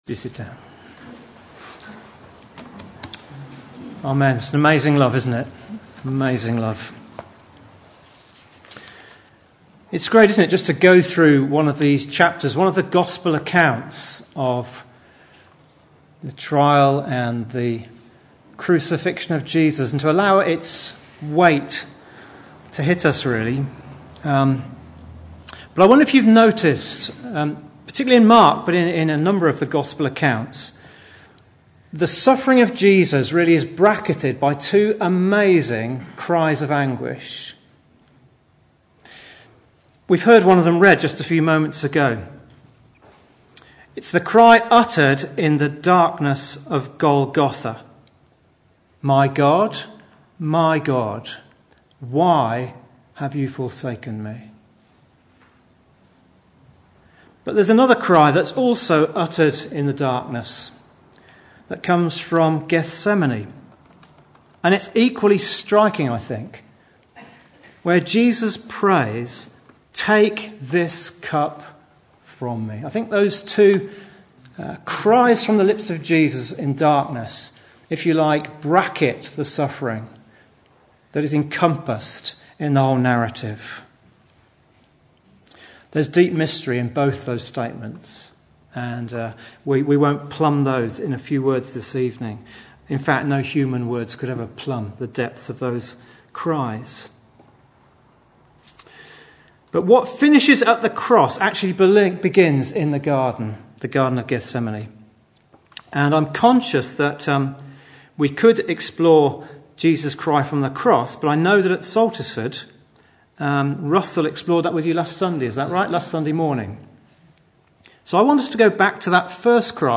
25 March 2016 Mark's Jesus View Sermons from all the years!